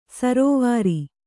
♪ sarōvāri